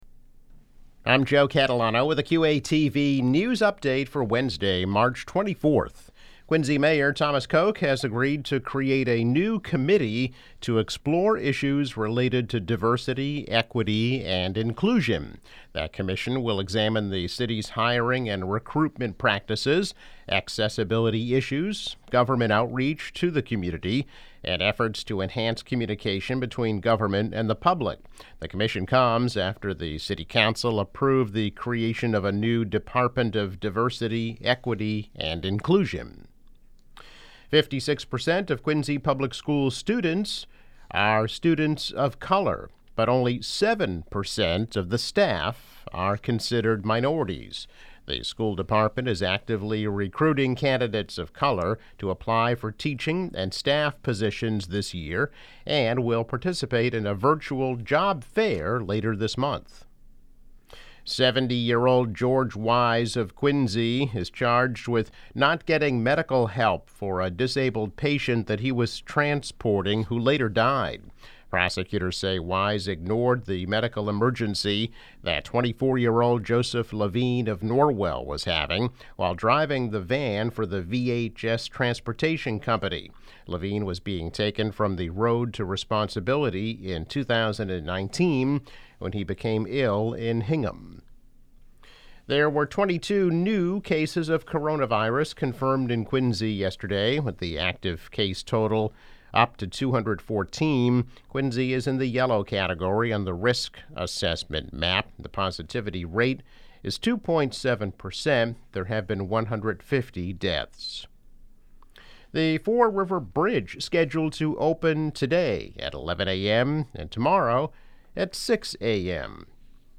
News Update - March 24, 2021